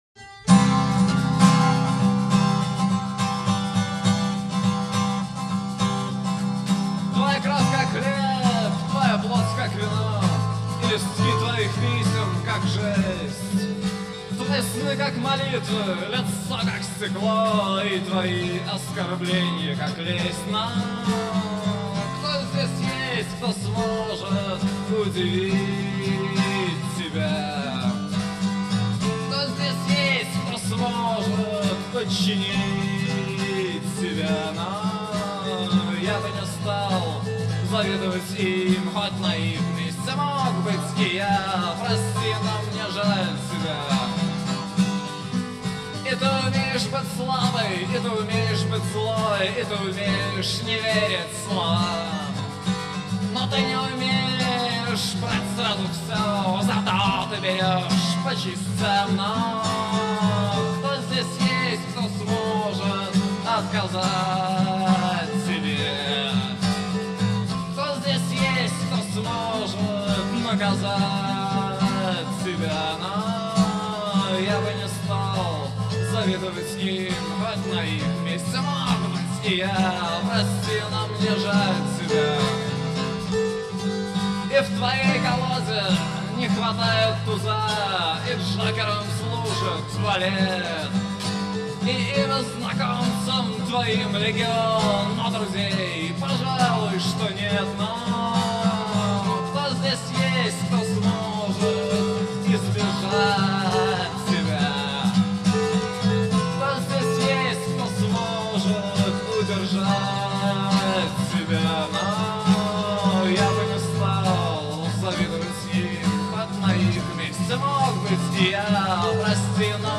губная гармошка, голос.